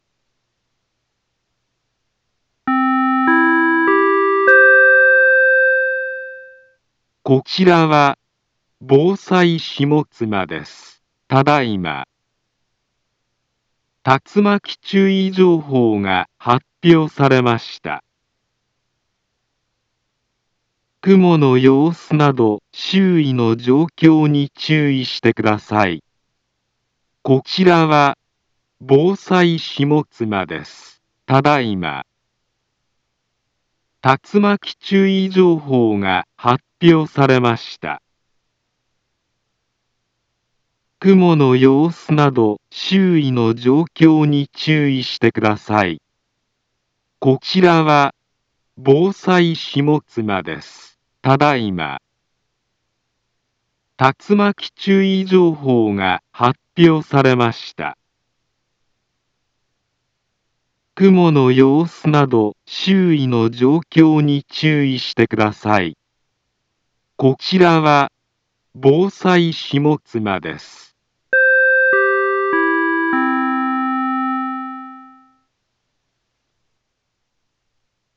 Back Home Ｊアラート情報 音声放送 再生 災害情報 カテゴリ：J-ALERT 登録日時：2023-08-01 10:45:09 インフォメーション：茨城県南部は、竜巻などの激しい突風が発生しやすい気象状況になっています。